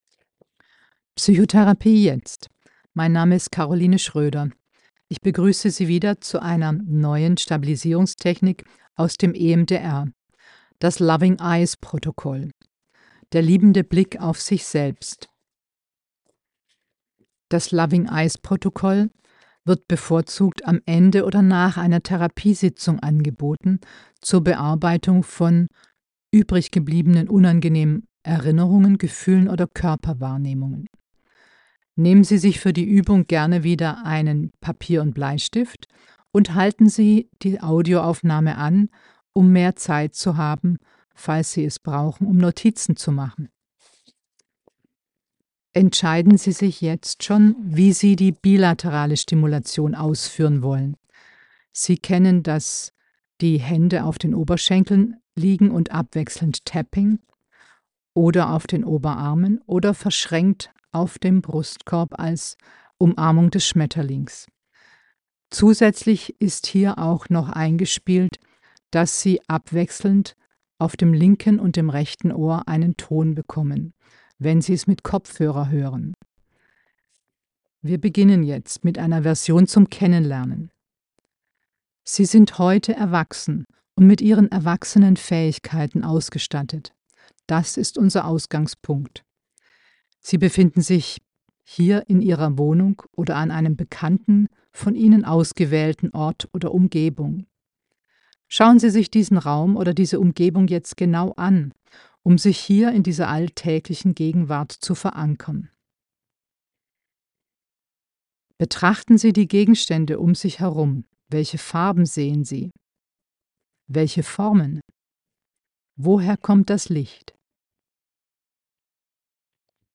Hier kombiniert mit BLS Bilateraler Stimulation, um die positive Wirkung zu vertiefen.